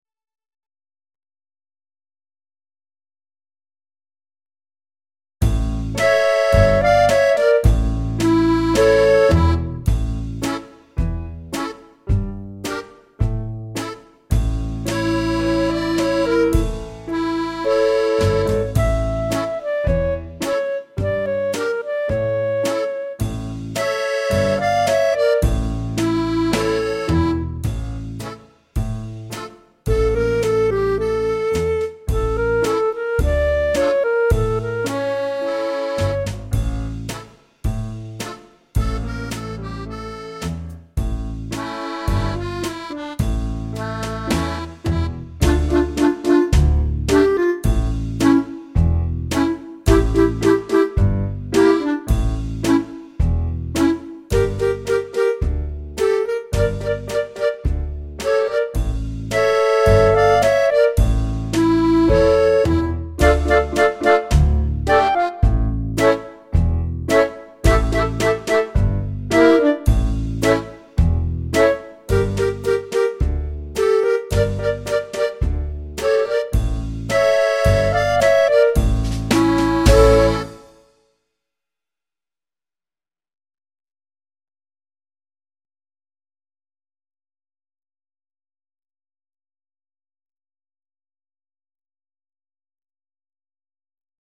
41 Oh Father! (Backing Track)